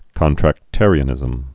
(kŏntrăk-târē-ə-nĭzəm)